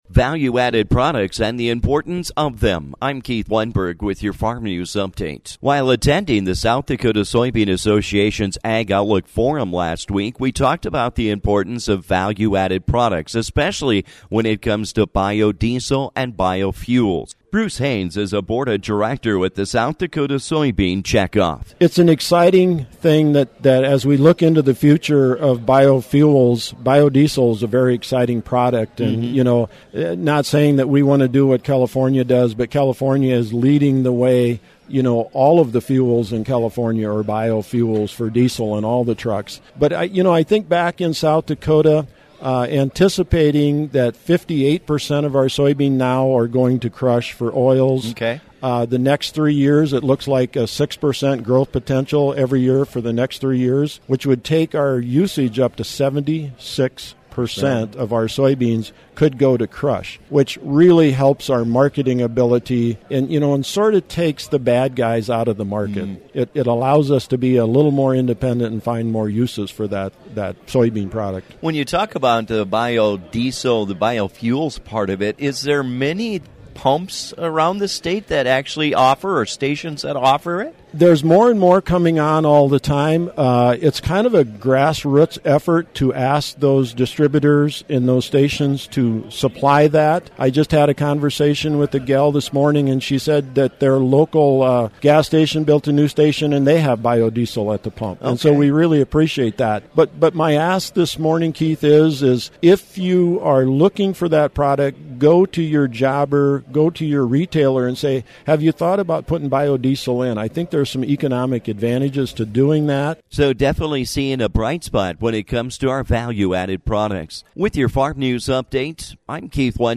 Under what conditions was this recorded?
While attending the South Dakota Soybean Association’s Ag Outlook in Sioux Falls last week, we heard that our Value-Added Products are offering a Bright Spot within the Ag Industry.